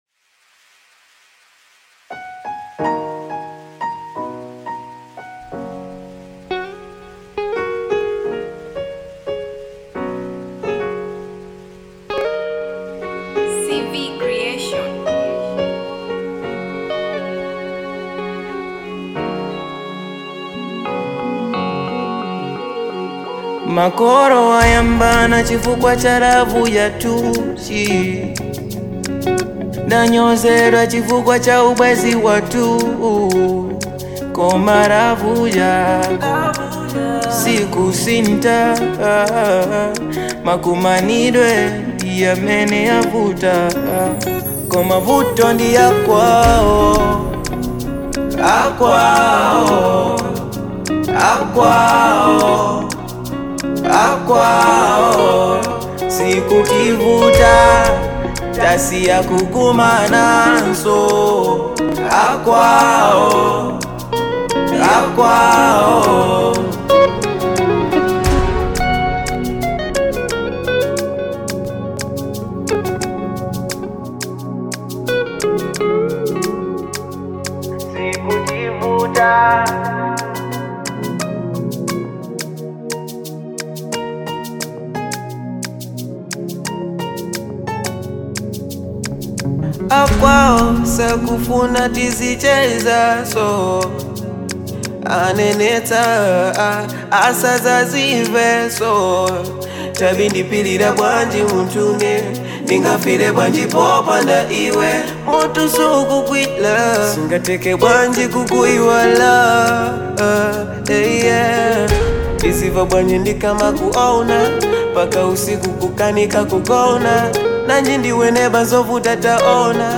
Genre : RnB
soulful R&B jam